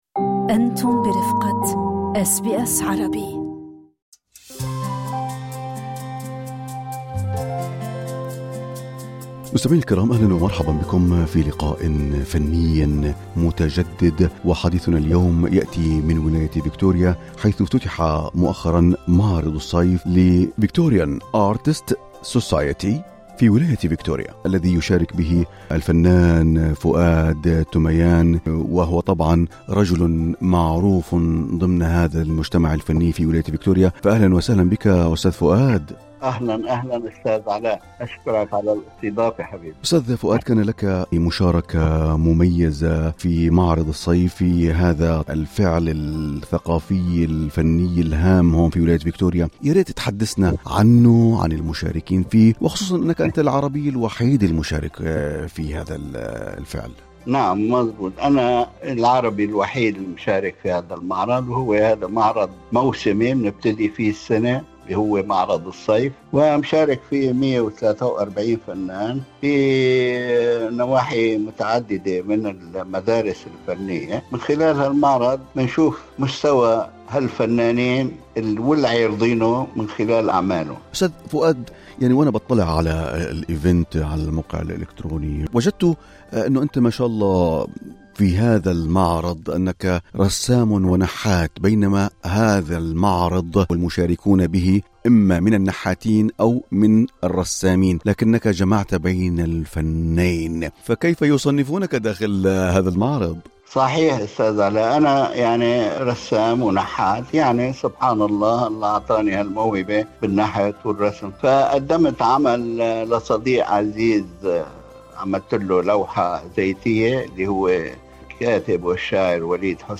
للاستماع لتفاصيل اللقاء، اضغطوا على زر الصوت في الأعلى.